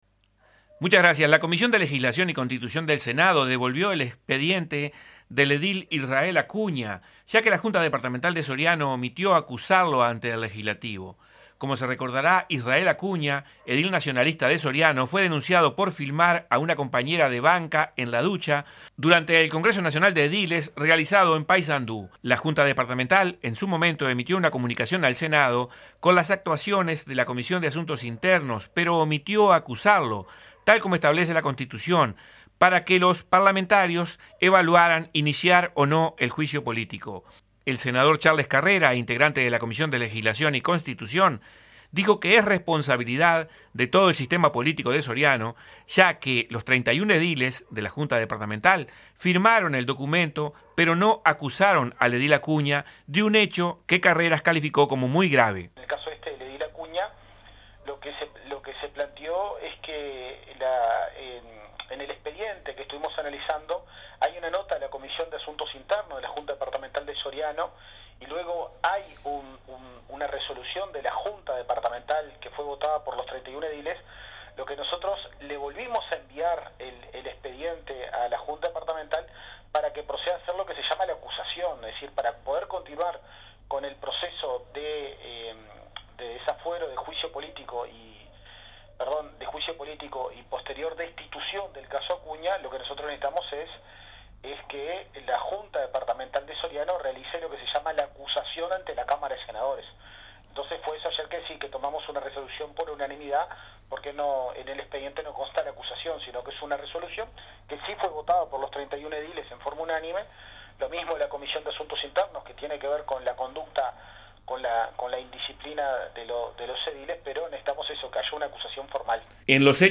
El senador del Frente Amplio Charles Carrera, integrante de la Comisión, dijo que la omisión es responsabilidad de todo el sistema político de Soriano.
Informe del corresponsal
charles-carrera.mp3